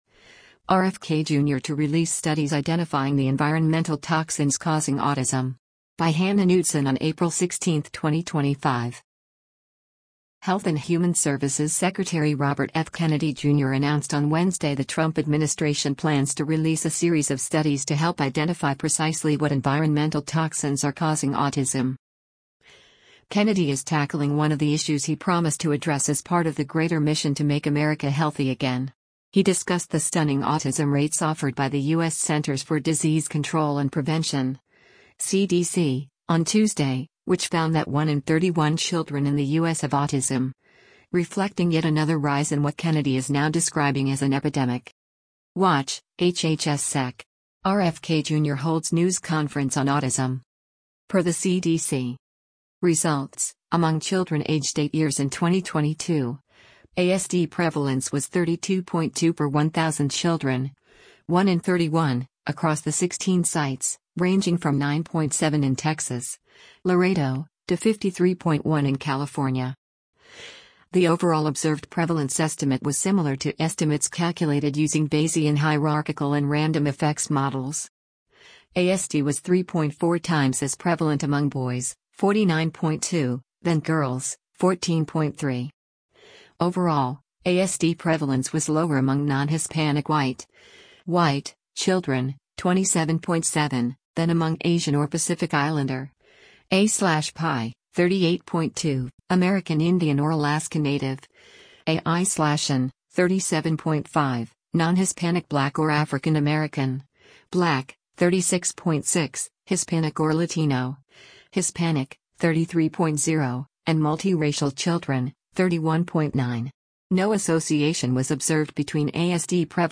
U.S. Secretary of Health and Human Services Robert F. Kennedy Jr. speaks during a news con
WATCH — HHS Sec. RFK Jr. Holds News Conference on Autism: